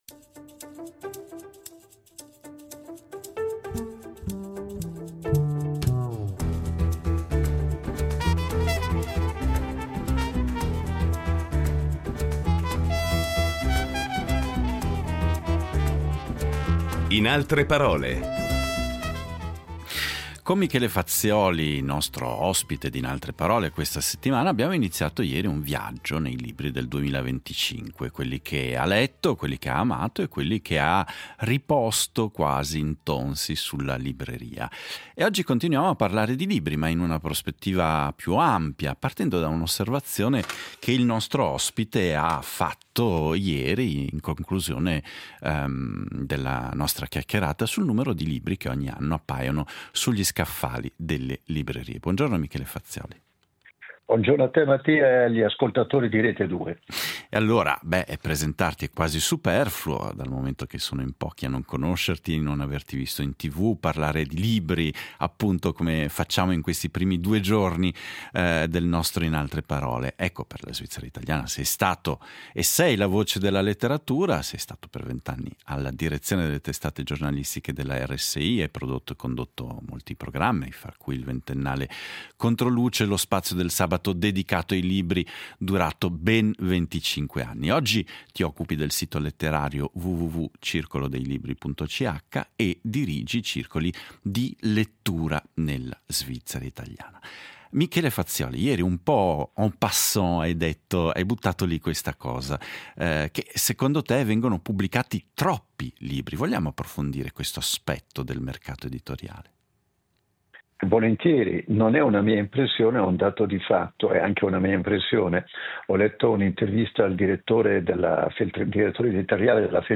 Incontro con il giornalista ed esperto di narrativa,